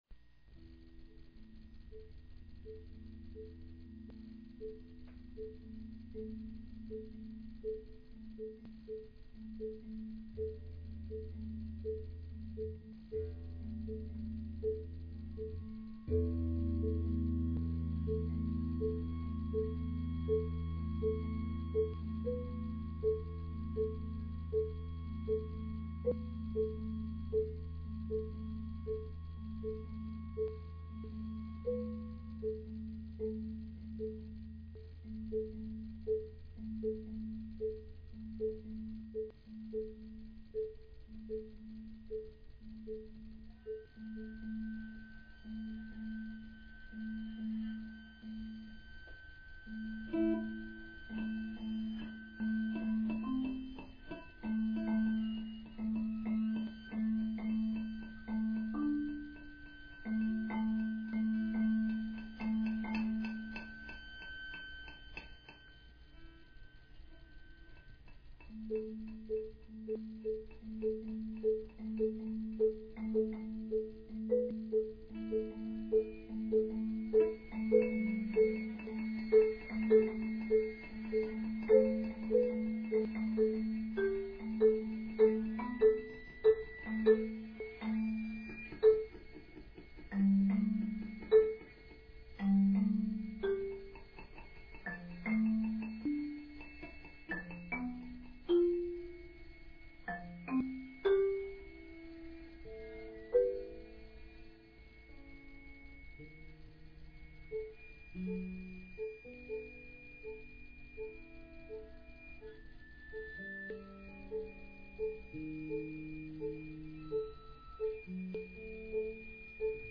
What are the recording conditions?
Recorded Live in MP3